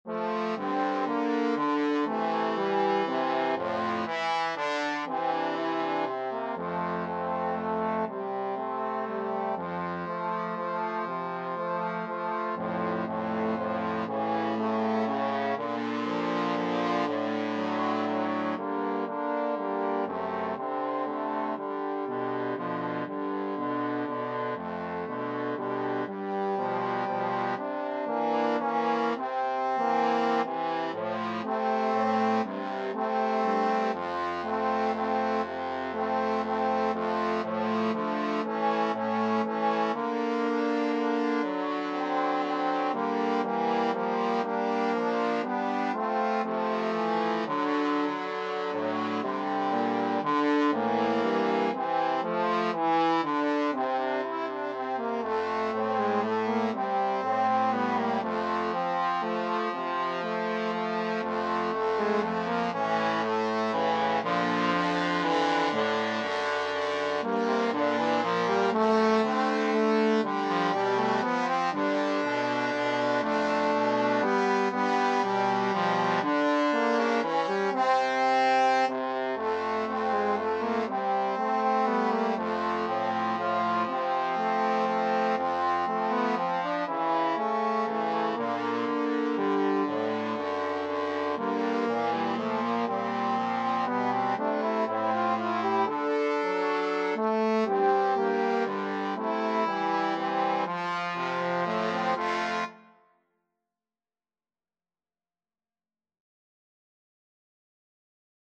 Trombone 1Trombone 2Trombone 3
= 120 Tempo di Valse = c. 120
3/4 (View more 3/4 Music)
Jazz (View more Jazz Trombone Trio Music)